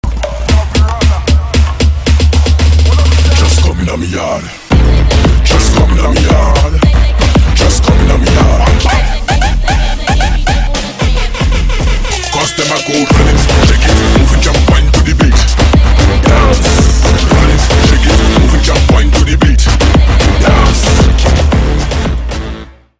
веселые
из рекламы с енотами